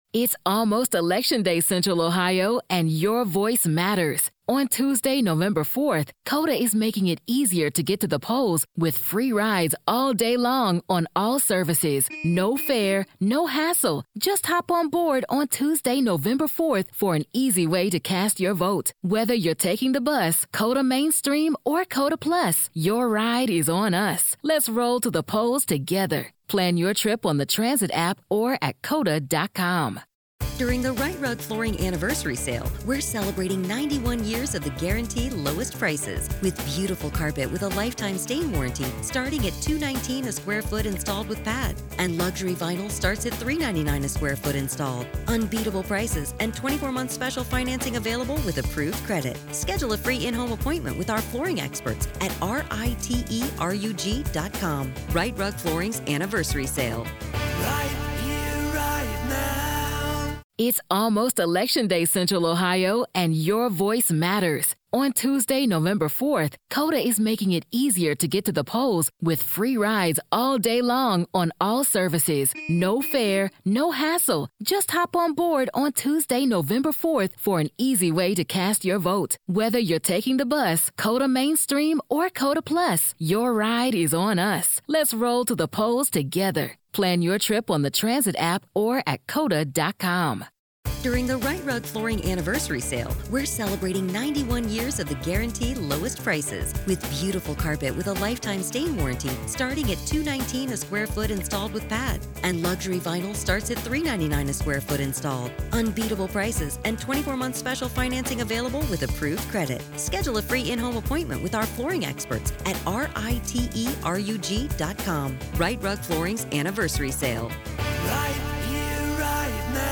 Telles Takes the Stand-Raw Court Audio-NEVADA v. Robert Telles DAY 7 Part 1